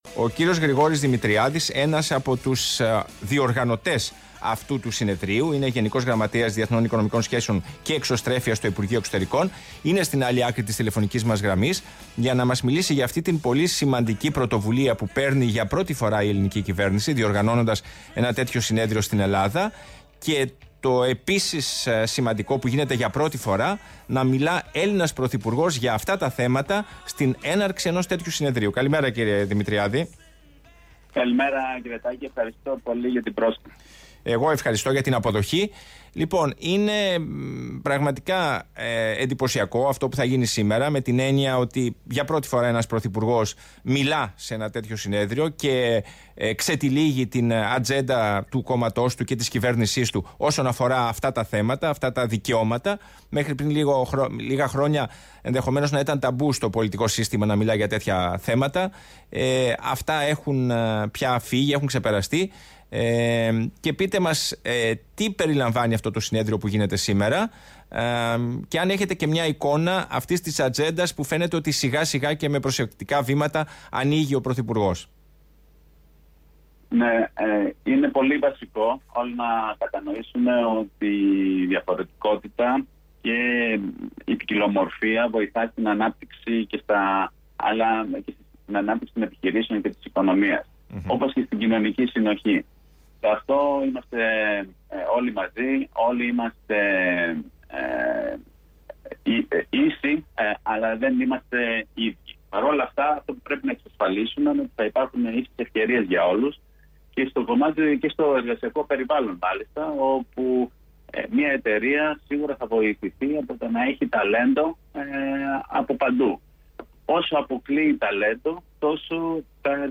συνέντευξη.